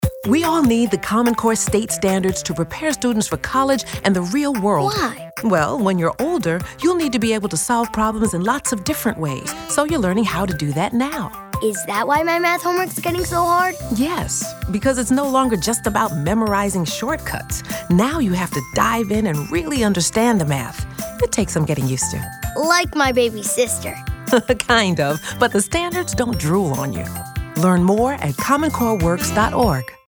Radio PSAs
Conversation - Math .mp3